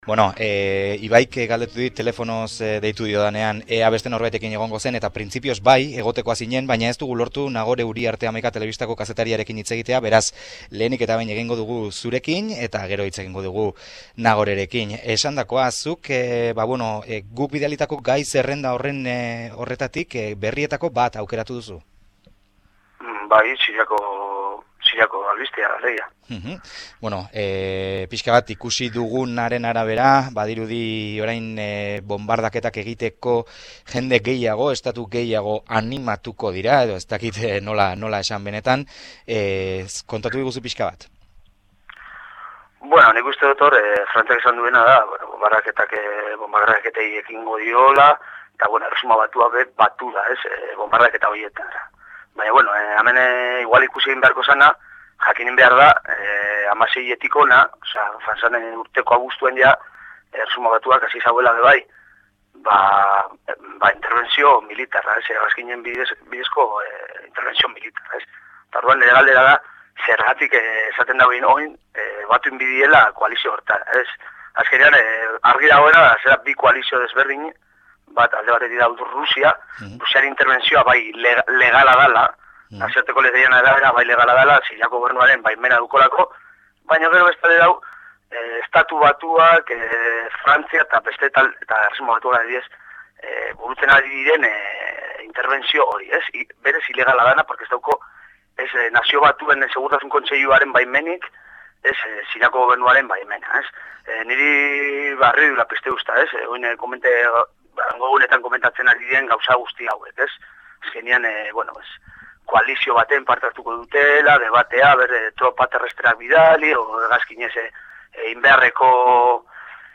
Eguneko izenburuak irakurri eta gaur ere bi kolaboratzaile izan ditugu Estatu Islamikoaren aurkako bonbardaketak komentatzeko: